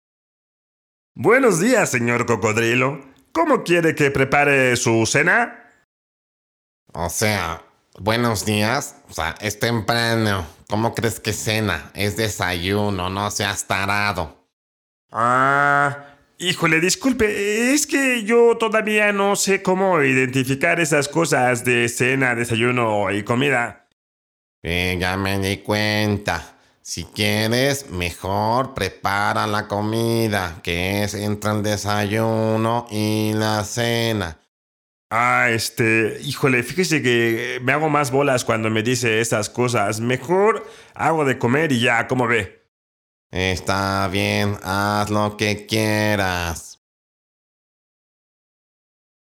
Spanish and english speaker, I'm a professional voice over actor.
Sprechprobe: Sonstiges (Muttersprache):